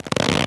foley_leather_stretch_couch_chair_08.wav